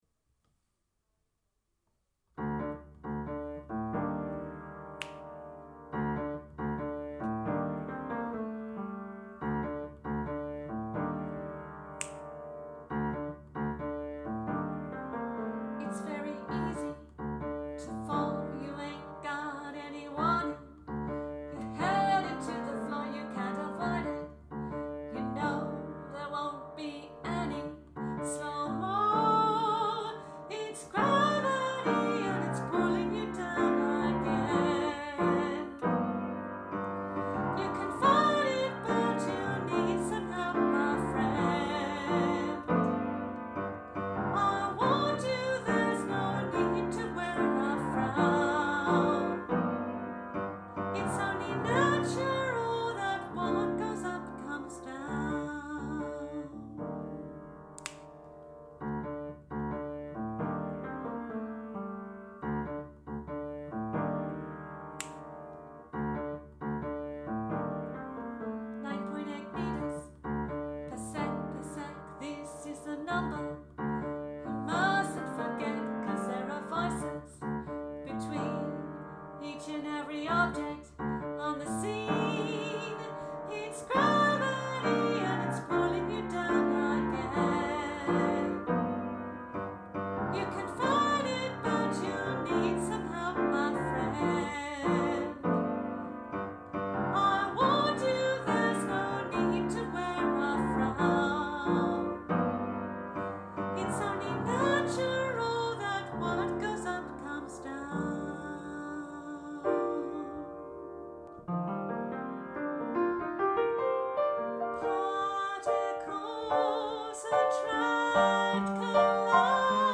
Unison voices with piano